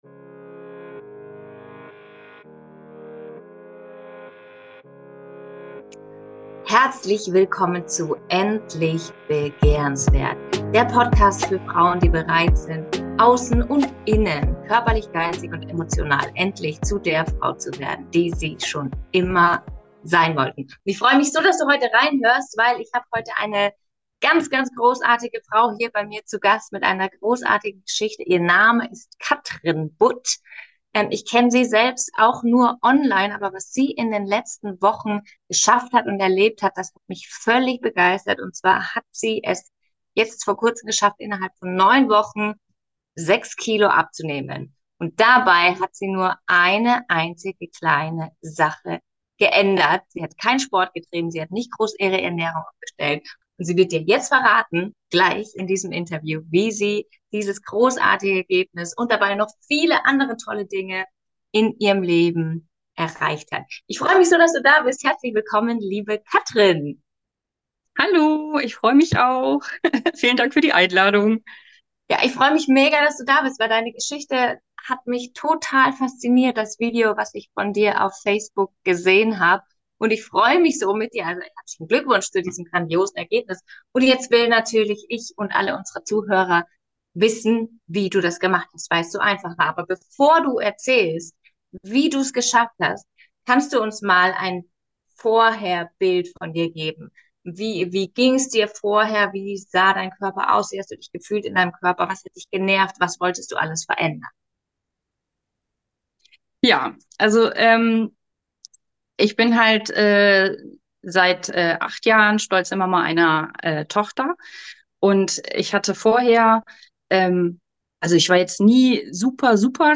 Beschreibung vor 1 Jahr Nach unzähligen gescheiterten Abnehmversuchen, hat sie es nun geschafft, in kompletter Leichtigkeit und ohne Quälerei, 6kg abzunehmen! In diesem spannenden Interview